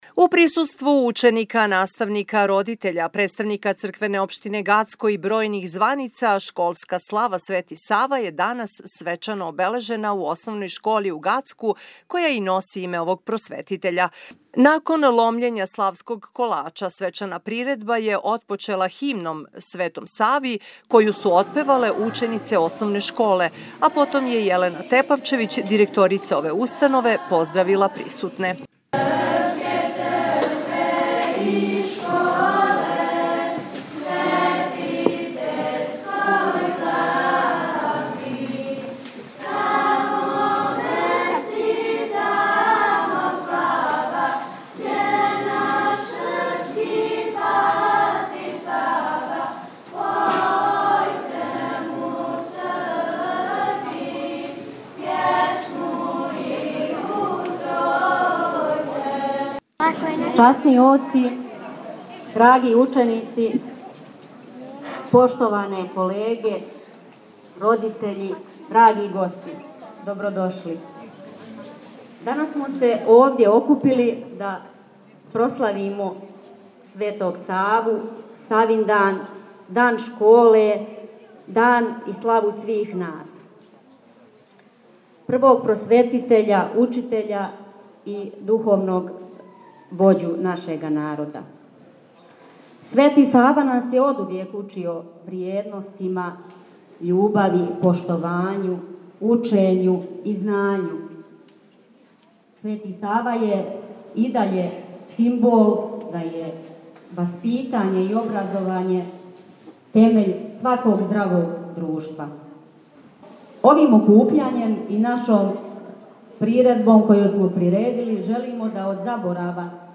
Након ломљења славског колача свечана приредба је отпочела Химном Светом Сави у извођењу ученица основне школе.